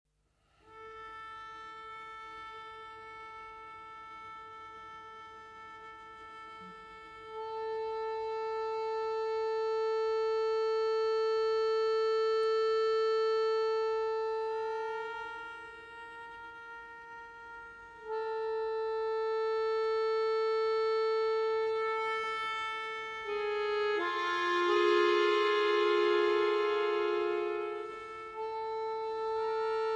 for violoncello and bayan